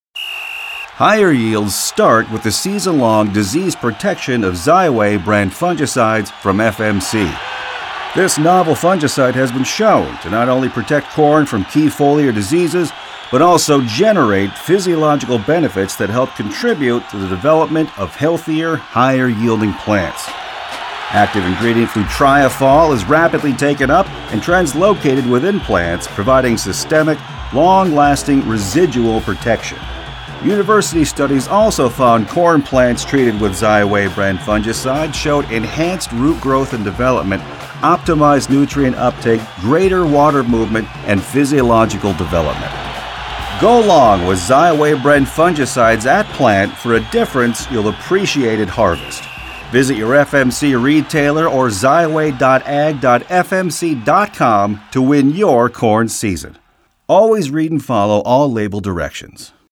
Four produced radio spots supporting print and digital campaigns.